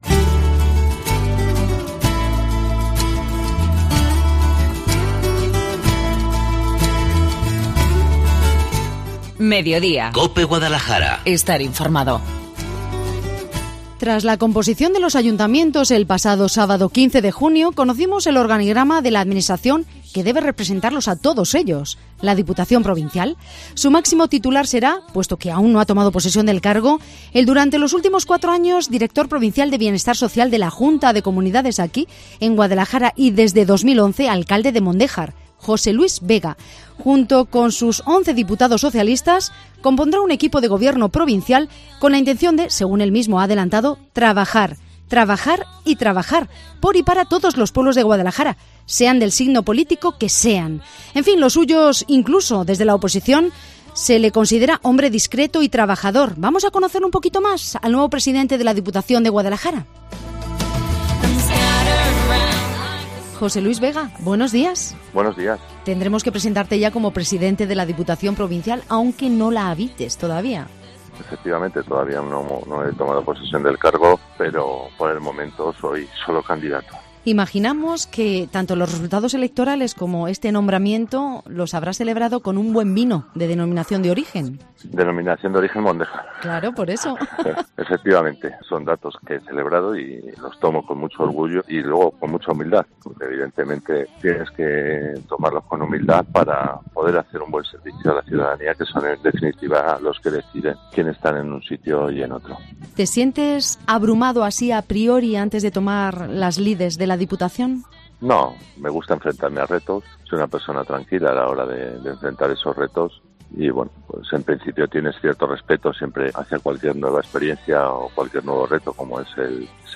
Charlamos con el socialista José Luis Vega, que, en pocos días, tomará posesión de su cargo como presidente de la Diputación Provincial de Guadalajara